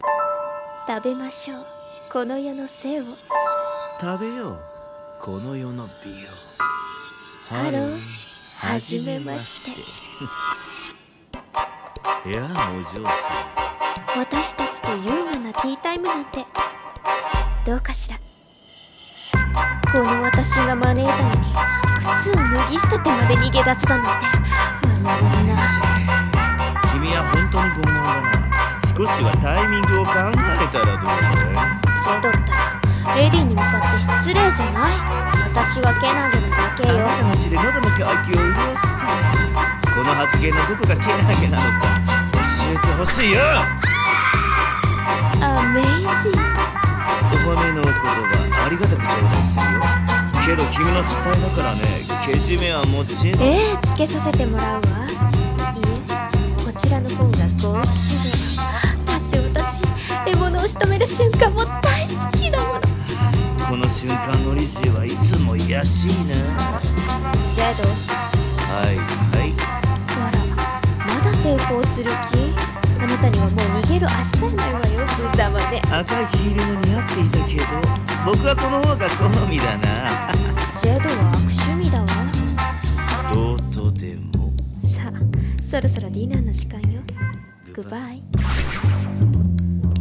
【声劇】Hello. Please die 【掛け合い】